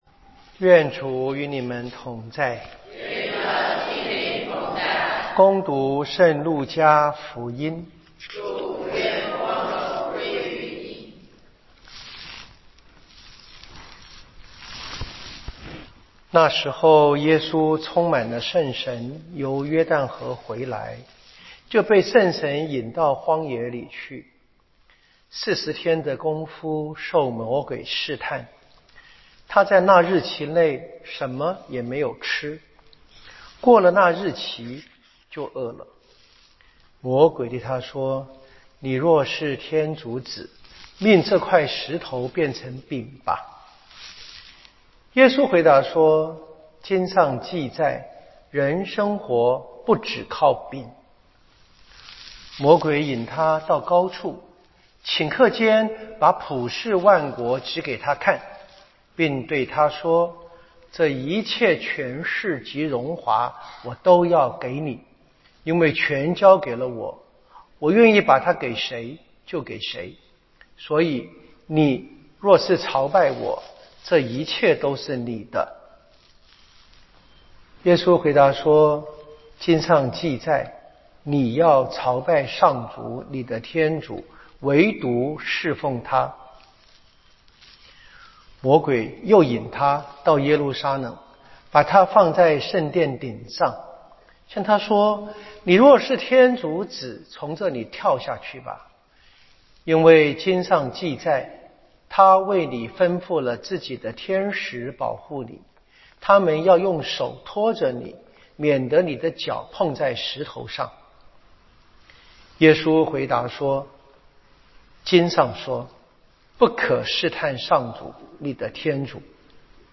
彌撒講道與聖經課程